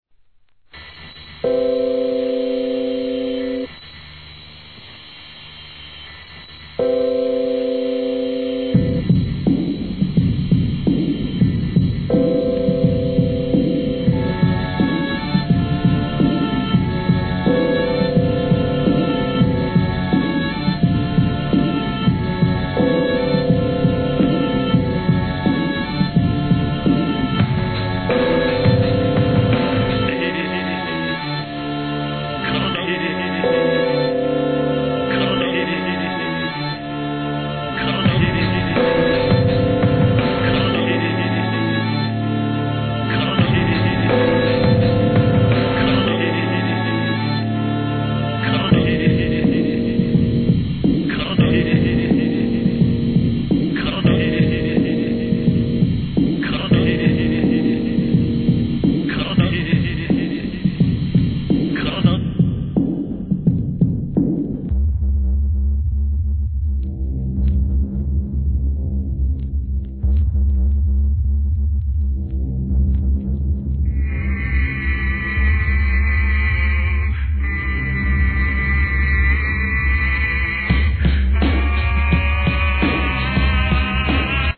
JAPANESE HIP HOP/R&B
ブレイク・ビーツ